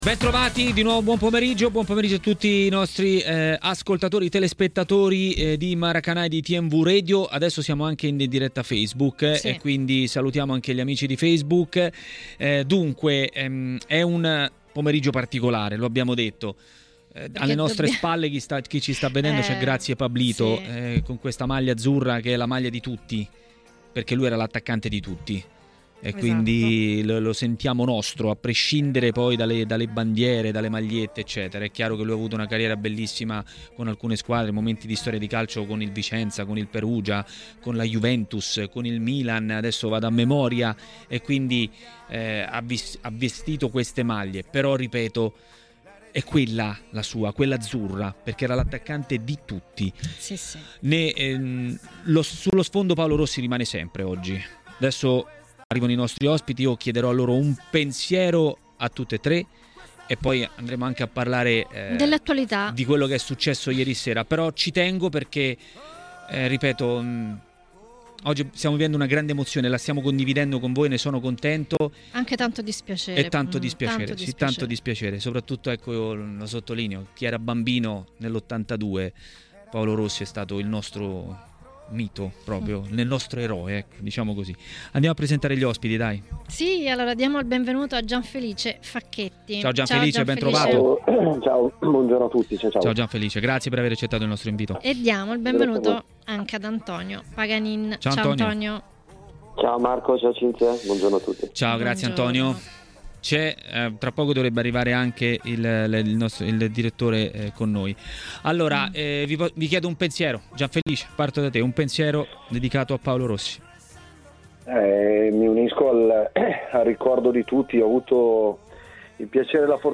Per commentare l'eliminazione dalla Champions dell'Inter a Maracanà, nel pomeriggio di TMW Radio, è intervenuto l'ex calciatore Antonio Paganin.